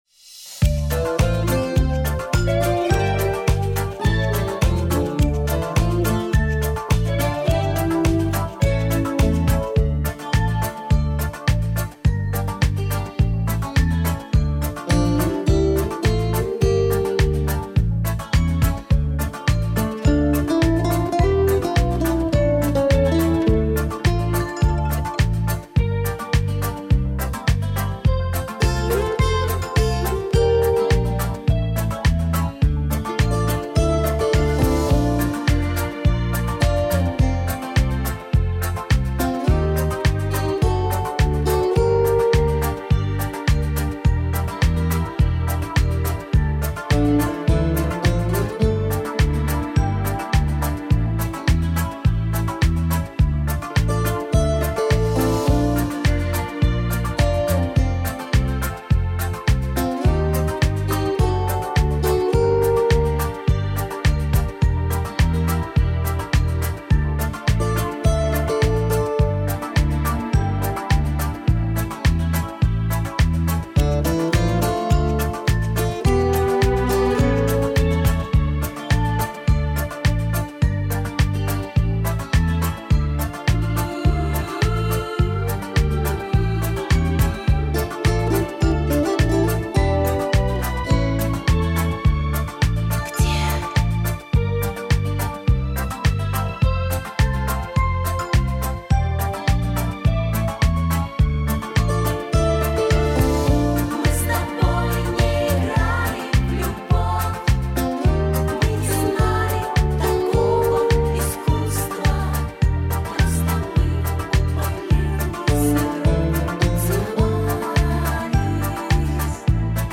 Минусовочка.